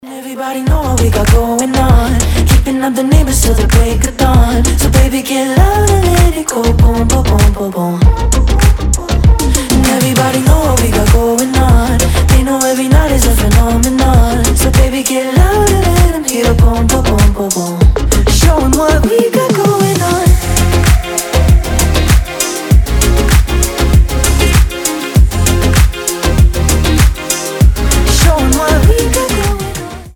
• Качество: 320, Stereo
ритмичные
мужской вокал
Midtempo
house
Ритмичный мидтемпо